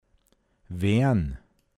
pinzgauer mundart
Wean, n. Wien